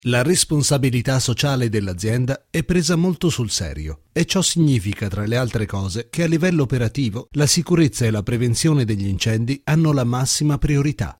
Sprechprobe: Sonstiges (Muttersprache):
I try to be an "any purpose" voice.